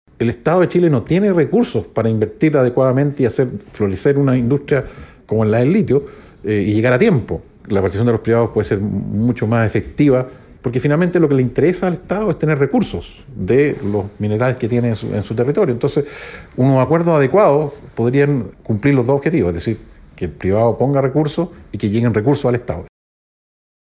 En la misma línea, el exministro de Minería, Hernán de Solminihac, dijo que para esta negociación y en la aplicación de esta política nacional, debe primar el pragmatismo, donde si bien el Estado puede tener el control, este debería centrarse en garantizar la recaudación.